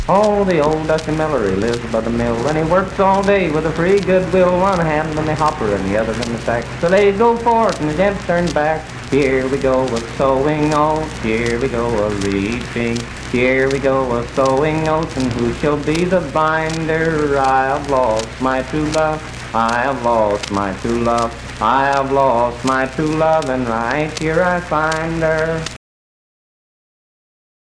Unaccompanied vocal performance
Verse-refrain 1(12).
Children's Songs, Dance, Game, and Party Songs
Voice (sung)
Spencer (W. Va.), Roane County (W. Va.)